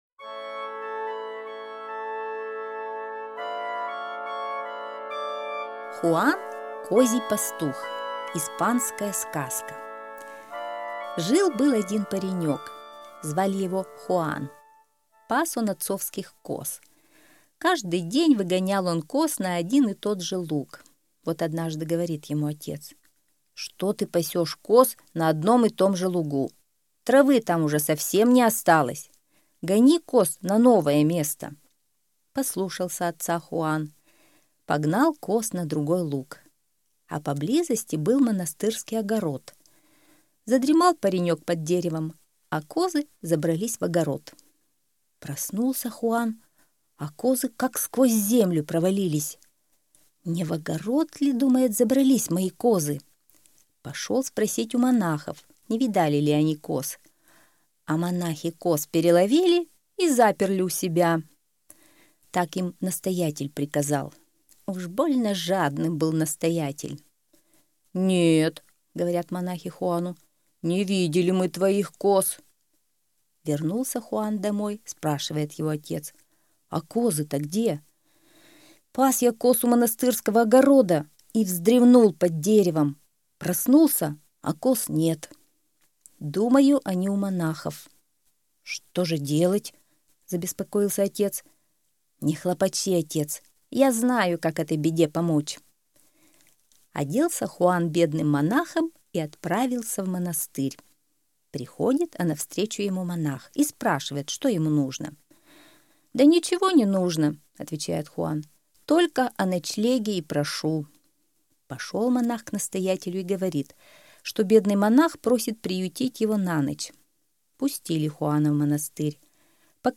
Хуан - козий пастух - испанская аудиосказка - слушать онлайн